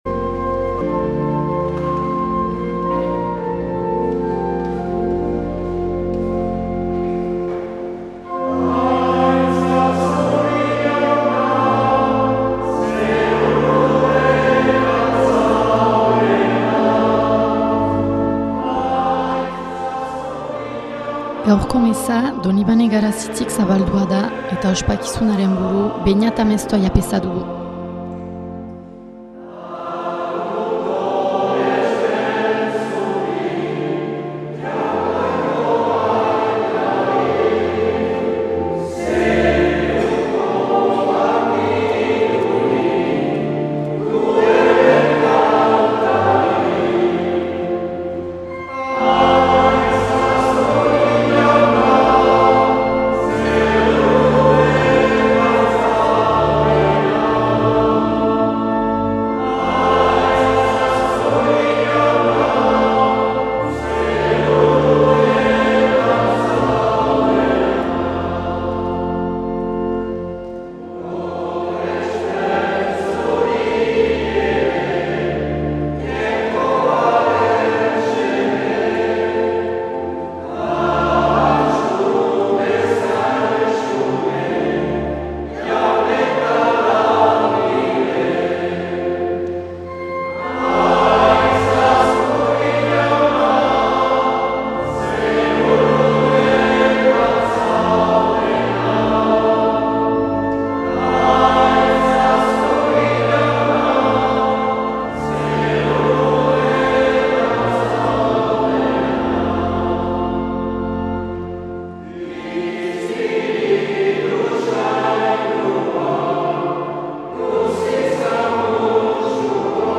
2022-06-12 Trinitate edo Hirutasun Saindua C - Garazi
Igandetako Mezak Euskal irratietan